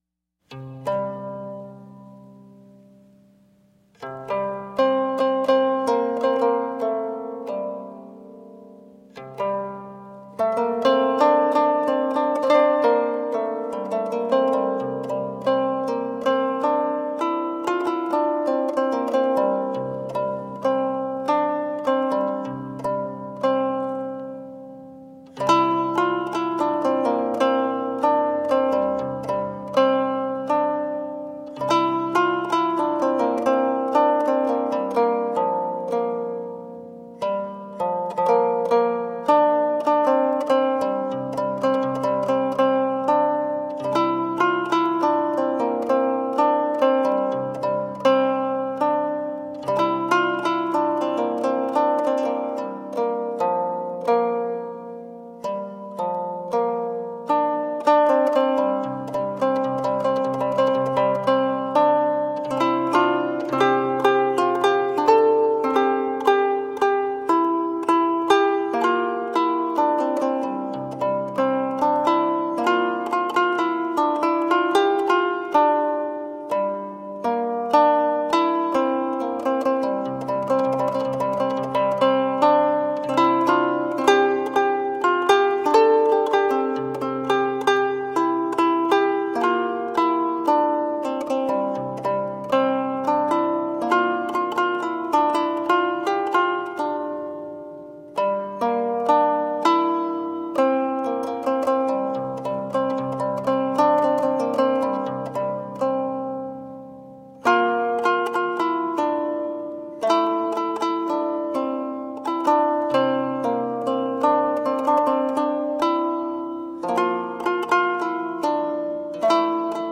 Medieval and middle eastern music.
13thC English